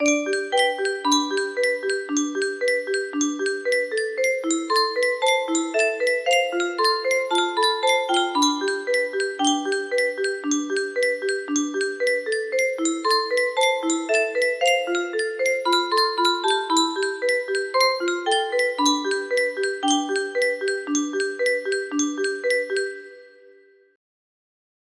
Rebel Yell (Music Box Arrangement
Music box version of the theme from my concept trailer for a fictional movie called Rebel Yell